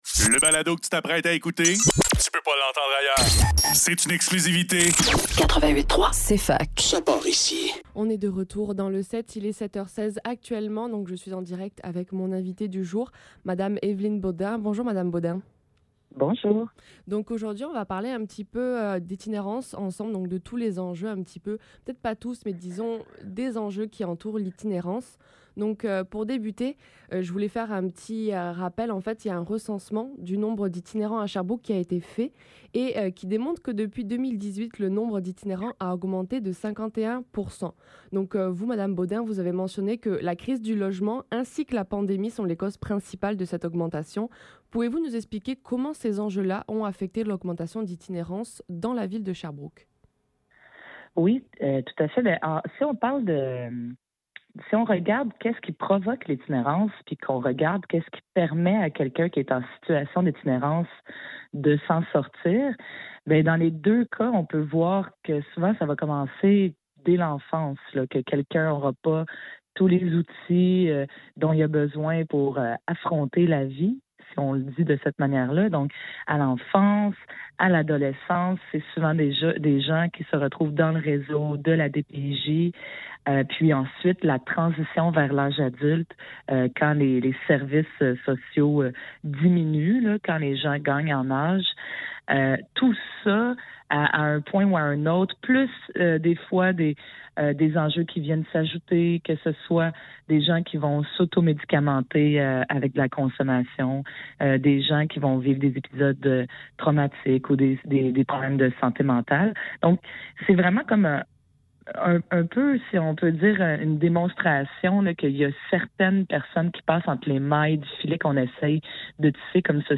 Le SEPT - Entrevue Évelyne Beaudin - 27 septembre 2023
Entrevue-veline-Beaudin.mp3